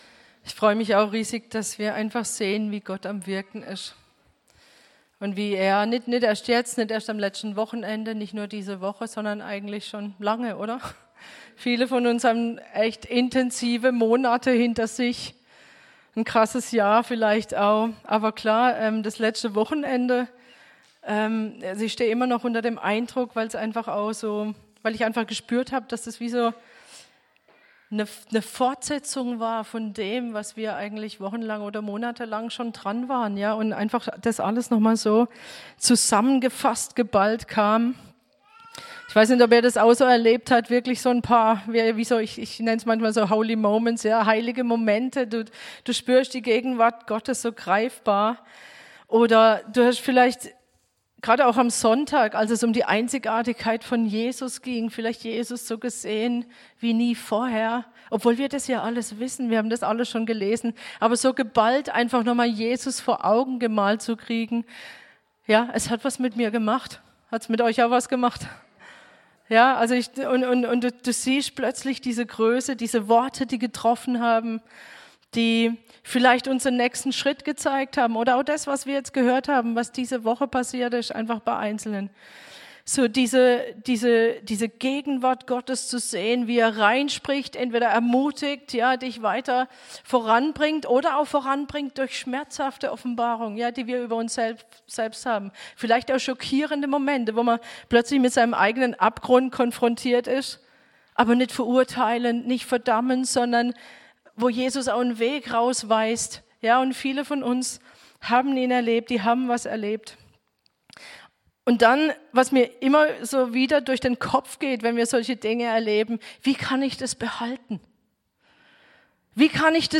Predigt Pfingsten 2025